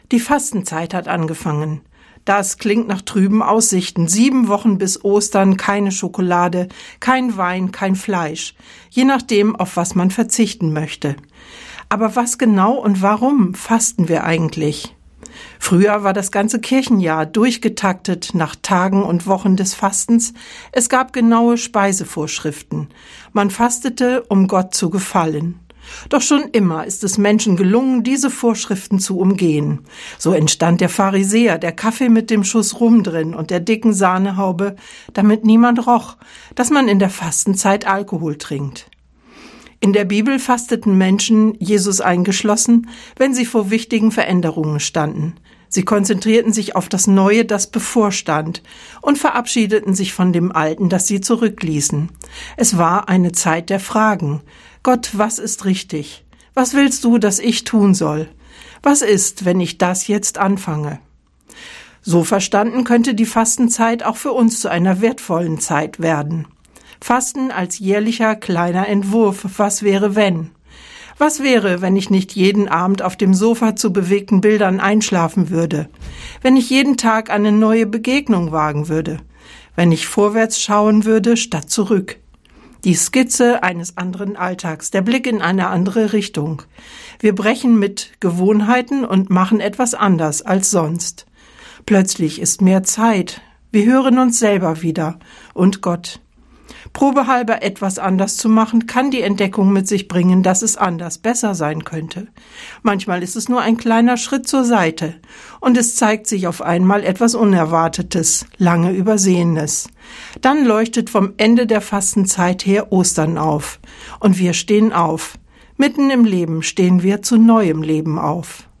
Radioandacht vom 6. Februar